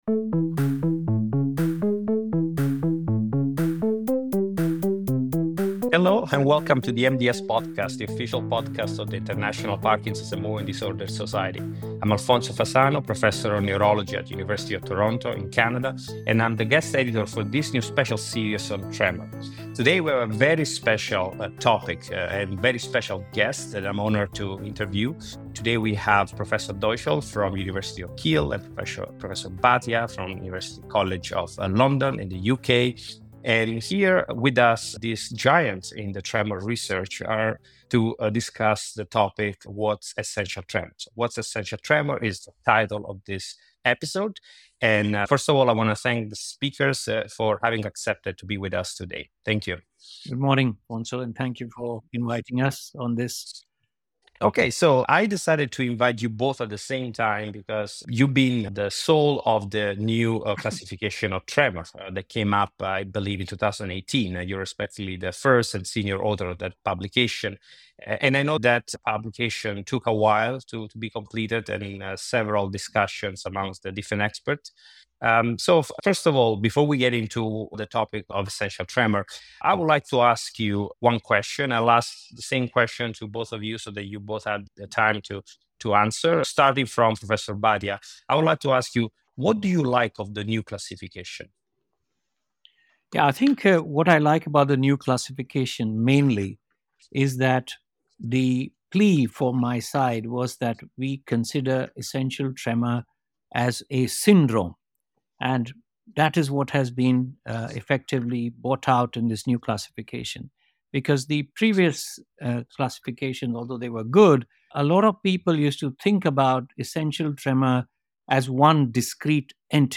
In this episode, the two souls at the center of the new tremor classification join the MDS Podcast to discuss what essential tremor is.